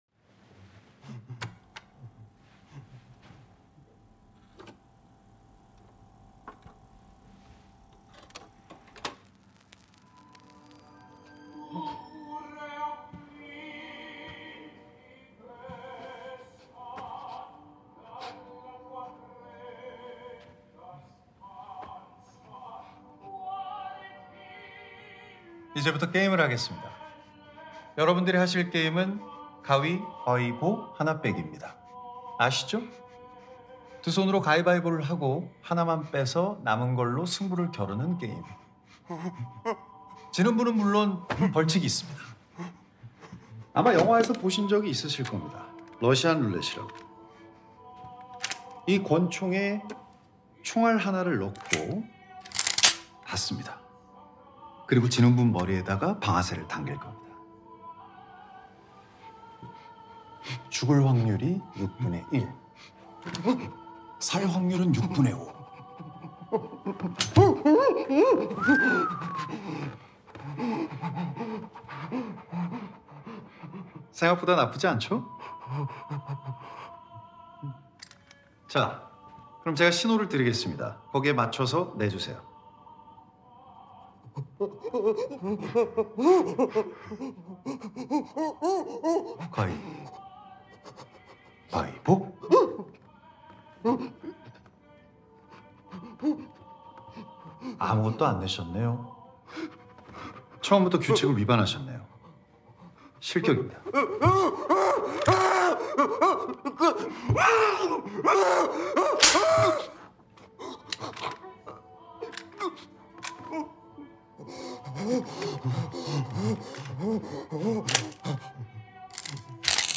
opera piece